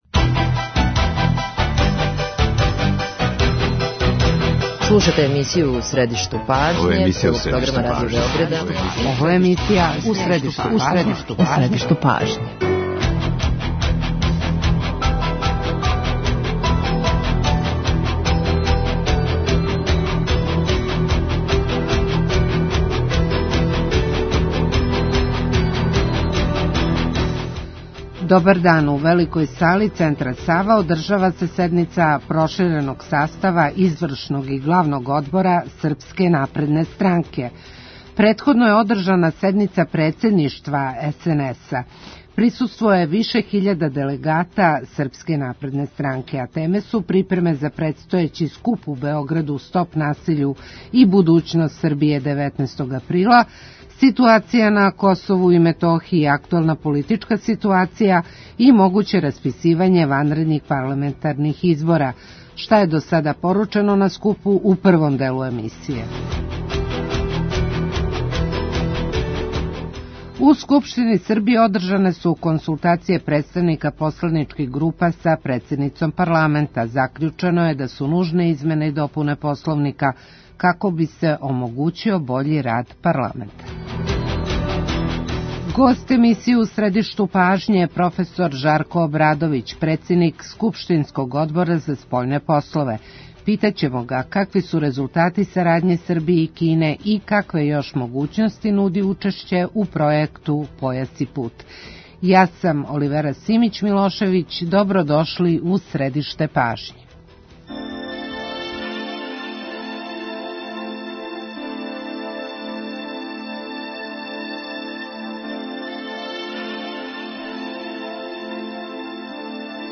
Гост емисије је проф. Жарко Обрадовић, председник Скупштинског одбора за спољне послове.